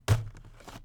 wheat_punch_5.wav